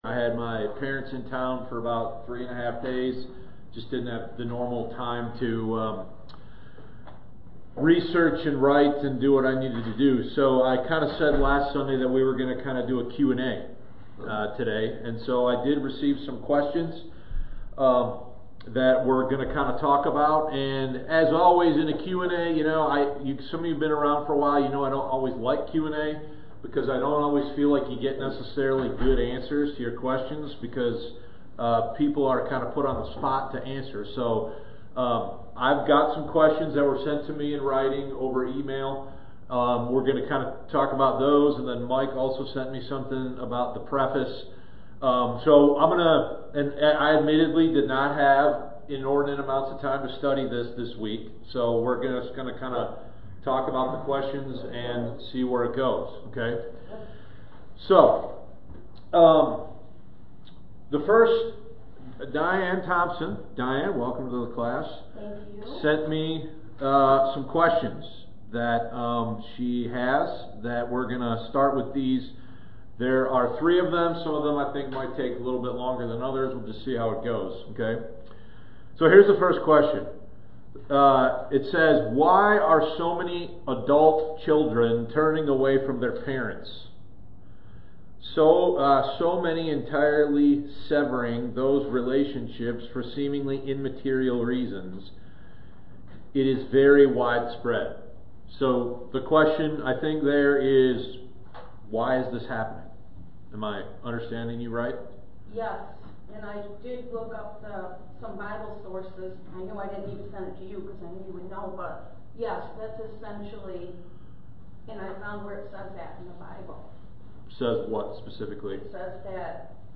Adult Sunday School Q&A: Children Abandoning Their Parents, Israel & the 144,000, & Pride